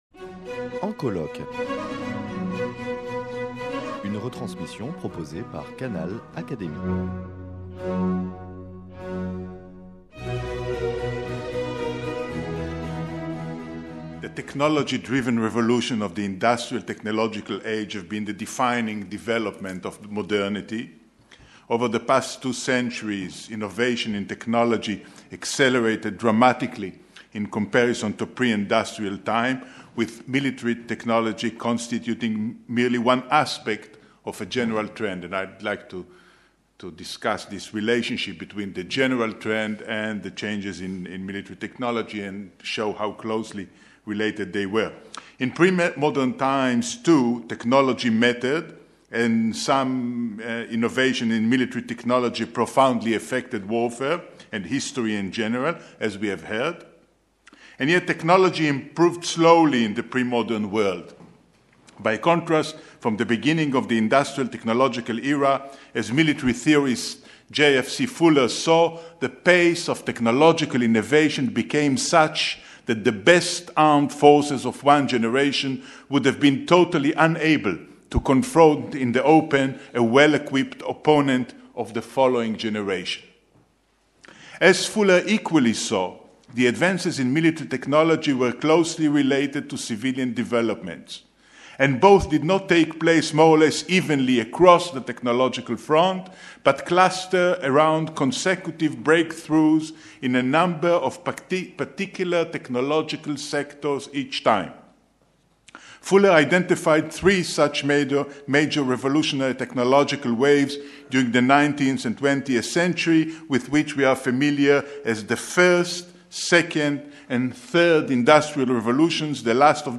prononcée le 14 octobre 2016 lors des journées d’étude « Guerre et technique »